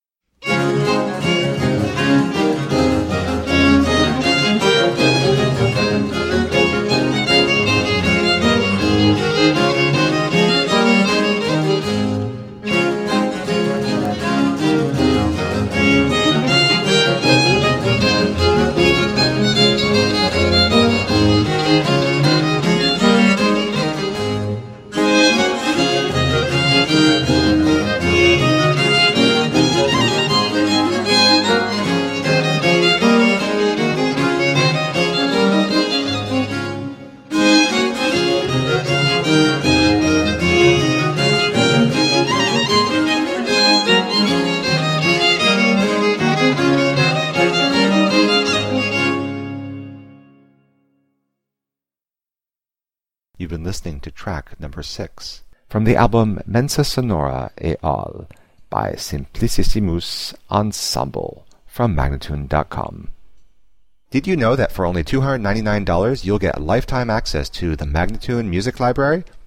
17th and 18th century classical music on period instruments.